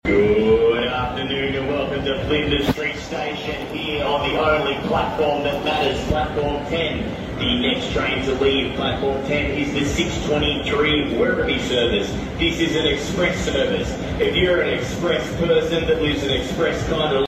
Express announcement only in Melbourne sound effects free download